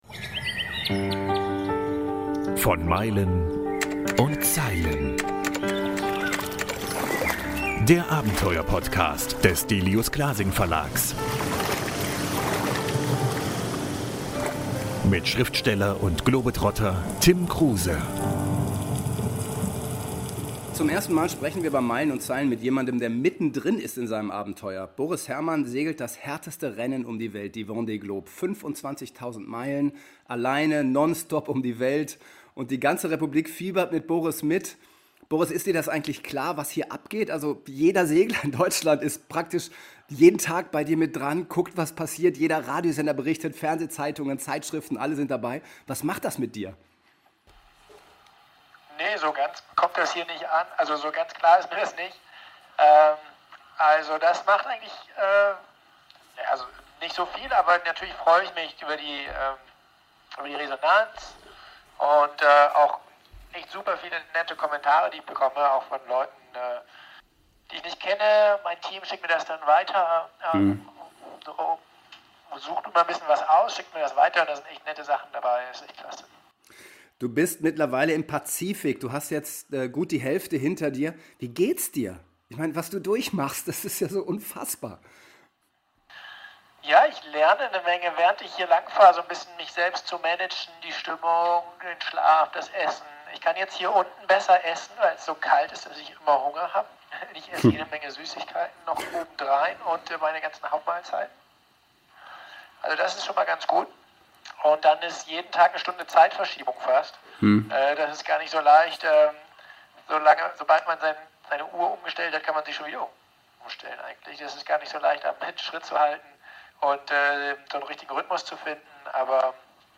Special - Boris Herrmann Live-Interview von der Vendée Globe ~ Meilen und Zeilen Podcast
Boris Herrmann live von Bord!